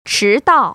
[chídào] 츠따오  ▶